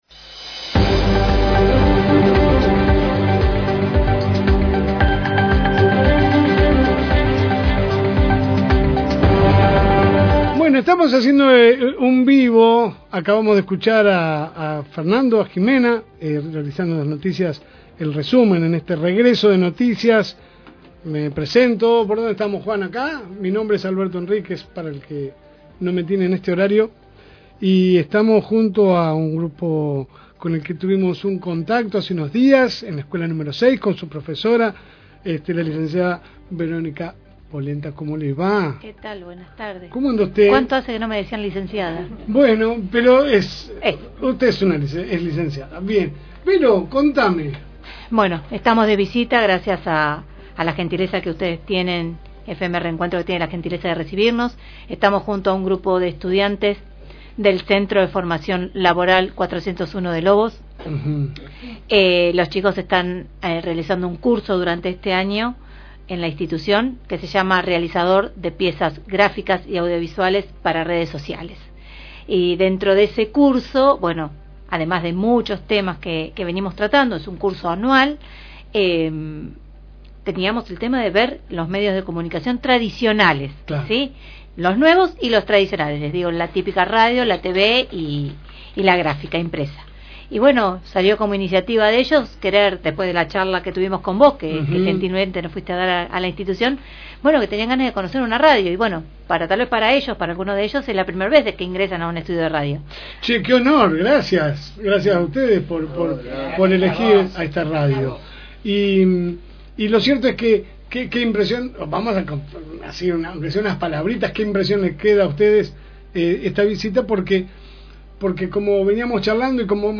Visita en la radio